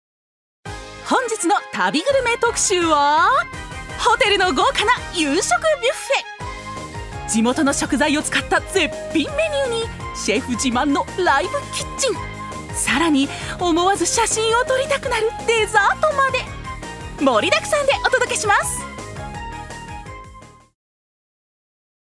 ナレーション６